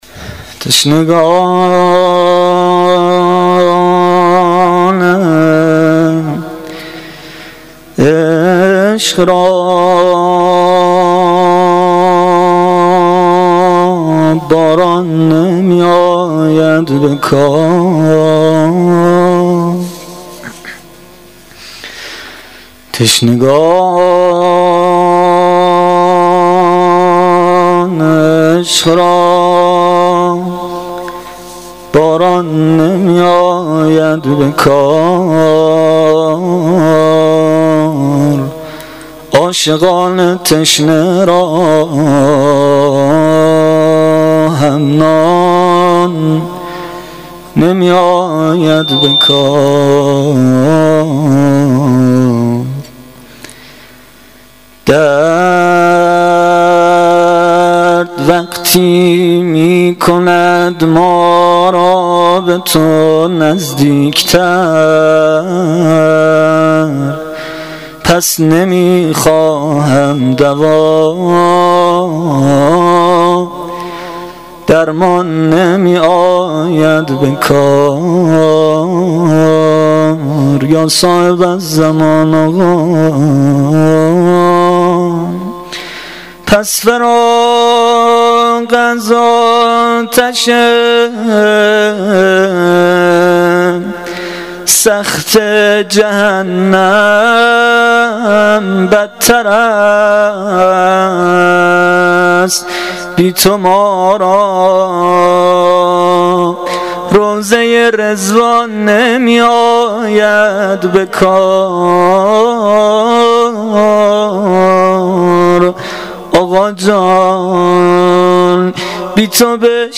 مناجات شب پنجم محرم الحرام 1396
• Shabe05 Moharram1396[02]-Monajat.mp3